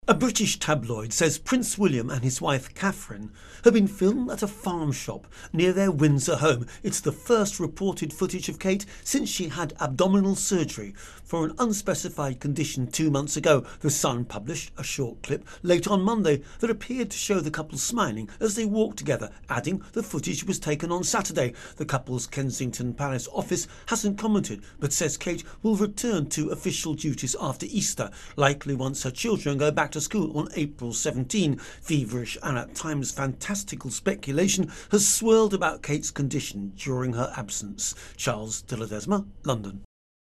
reports on an unofficial royal walkabout which has excited the British press.